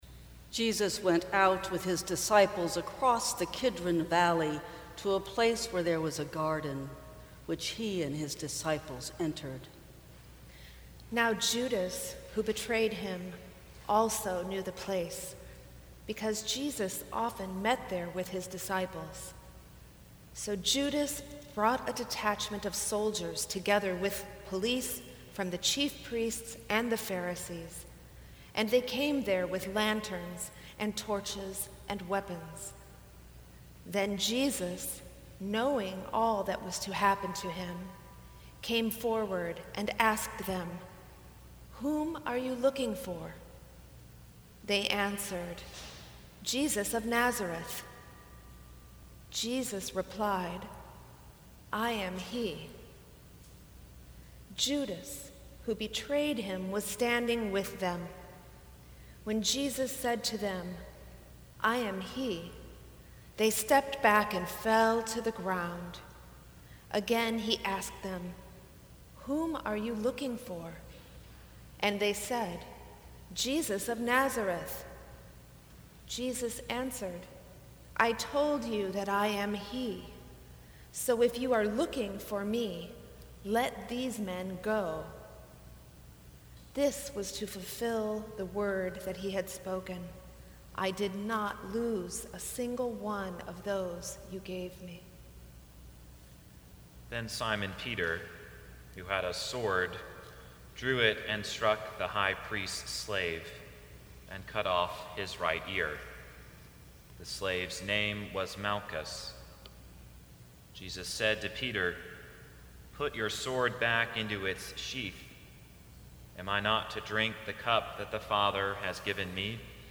Good Friday
Sermons from St. Cross Episcopal Church Truth and Betrayal Mar 30 2018 | 00:27:57 1x 00:00 / 00:27:57 Subscribe Share Apple Podcasts Spotify Overcast RSS Feed Share Link Embed